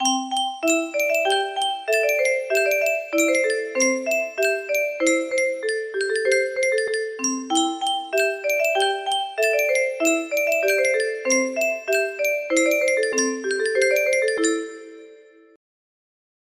Yunsheng Music Box - Unknown Tune Y524 music box melody
Full range 60